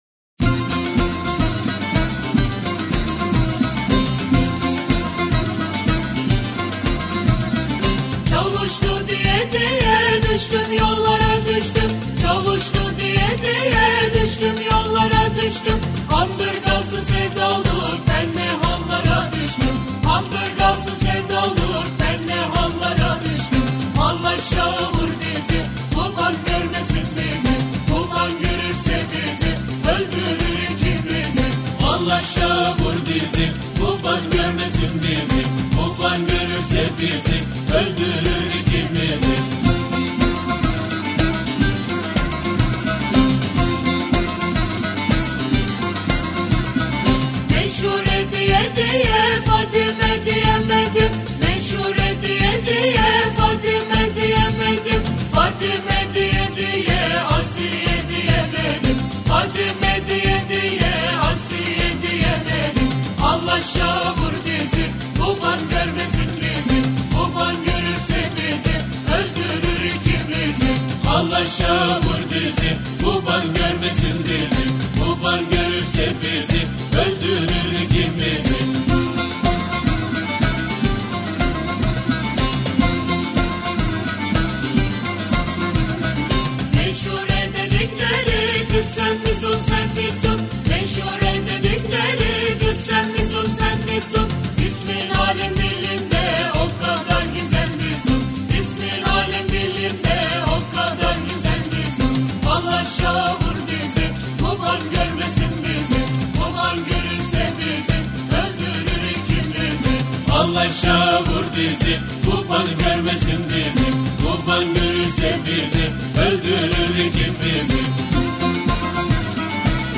Etiketler: türkiye, giresu, türkü